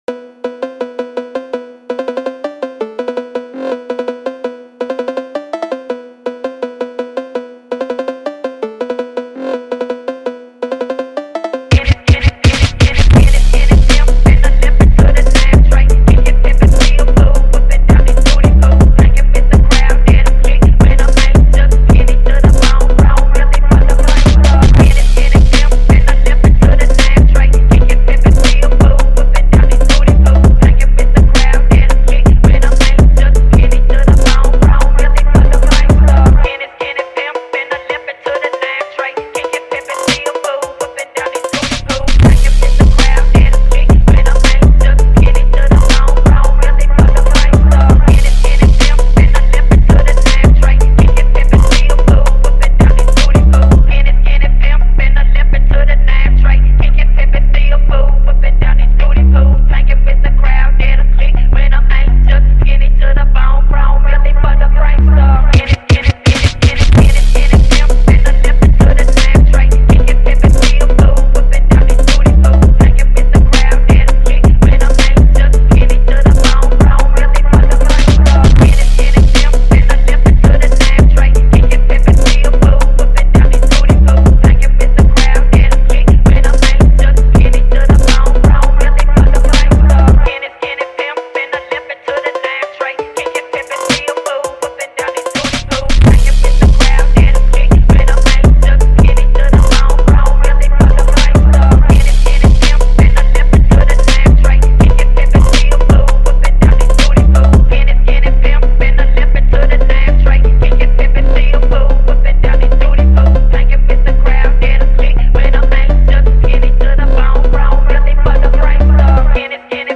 phonk.mp3